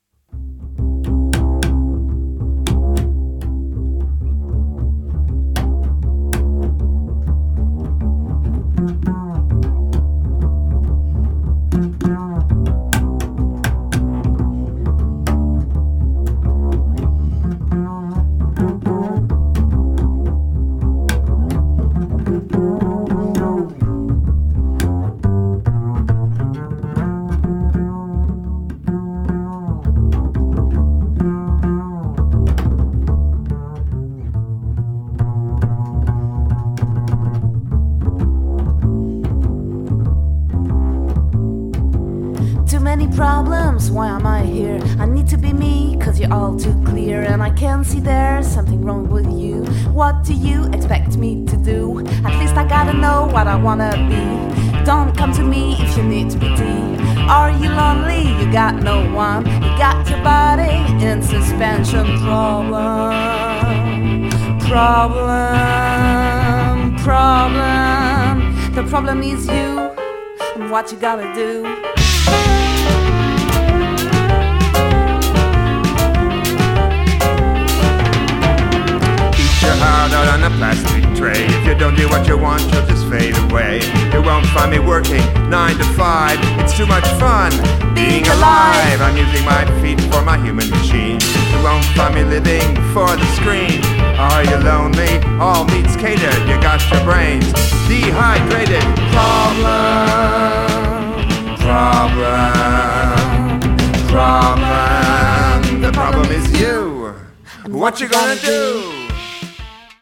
double-bass, voice, sh101
piano
saxophones
electric guitar
drums, percussion
Recorded May 2015 at Midilive and mixed Oct. 2015
A music that blends jazz, rock and song